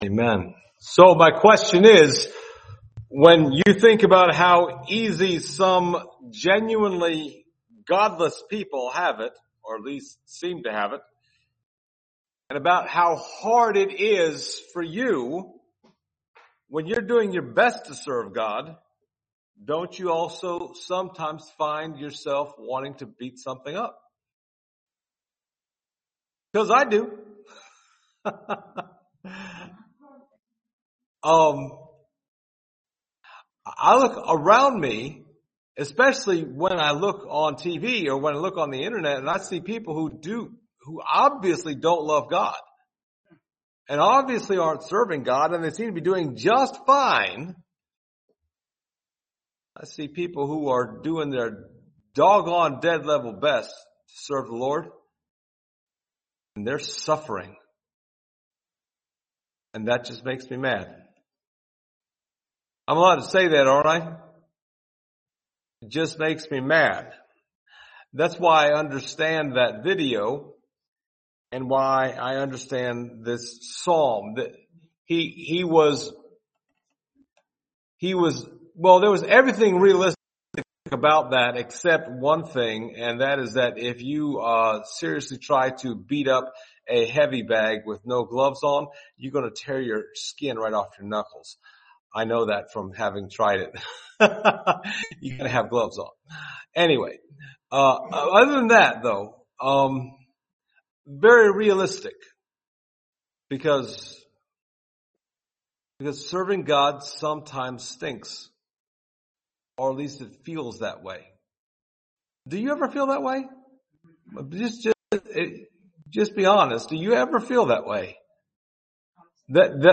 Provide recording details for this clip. Service Type: Sunday Morning Topics: doubt , frustration , rage